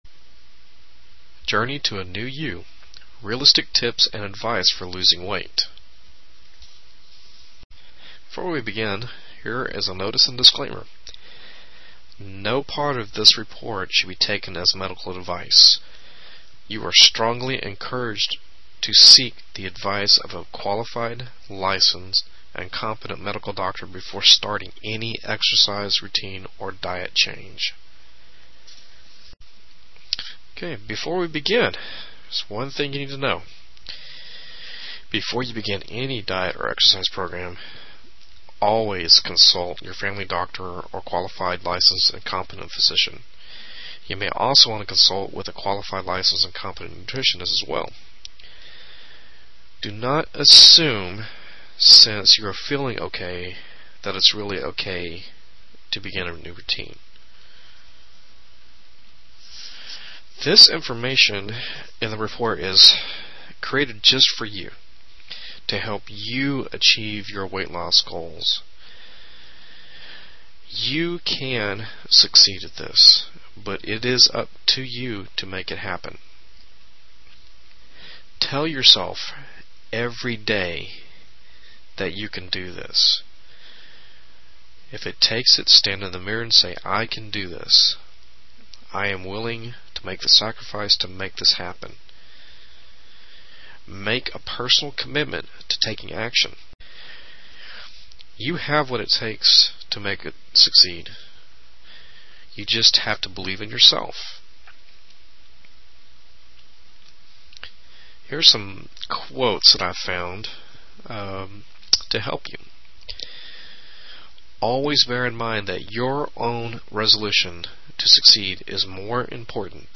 Plus it covers some simple secrets and nutritional gems that can help you avoid weight-gain and stay on the long-path to a healthy lifestyle. Plus, this bonus includes a free audio book.